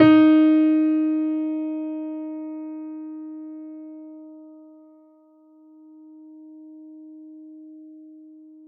interactive-fretboard / samples / piano / Ds4.wav
Ds4.wav